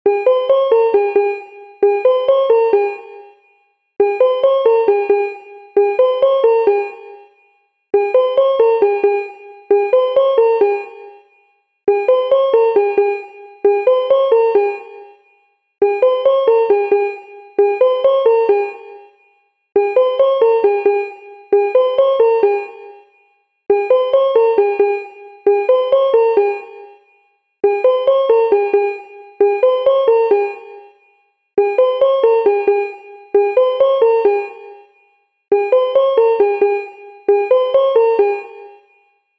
シンプルループの着信音は、無駄のないデザインと繰り返しの響きに焦点を当てています。